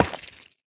Minecraft Version Minecraft Version latest Latest Release | Latest Snapshot latest / assets / minecraft / sounds / block / sculk_catalyst / place2.ogg Compare With Compare With Latest Release | Latest Snapshot